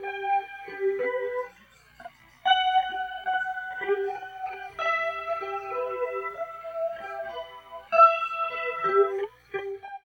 43 GUIT 1 -R.wav